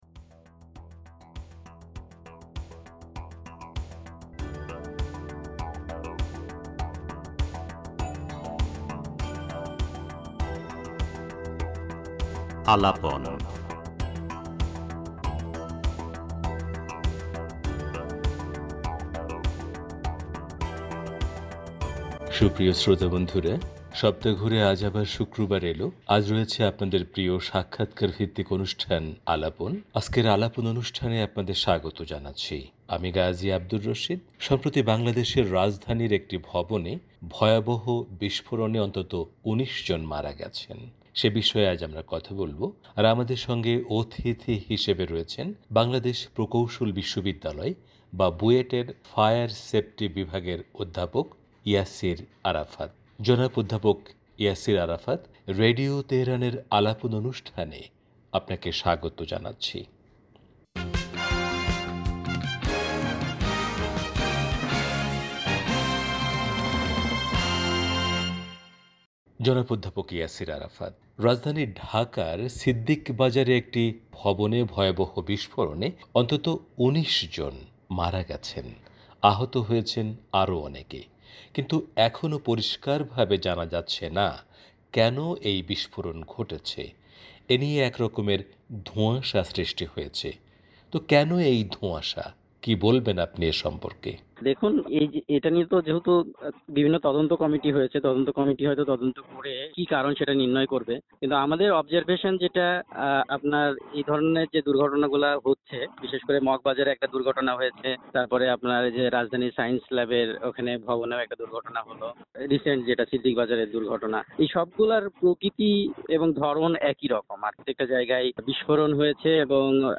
সাক্ষাৎকারে